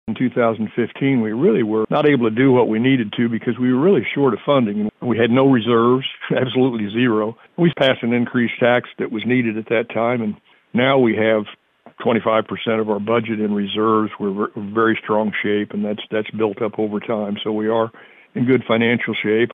Presiding Commissioner Ed Douglas looked back at the past 11 years, reflecting on changes instituted by the Livingston County commission and the citizens of the county.  Douglas explained much of this is possible because of a Sales Tax passed by the voters.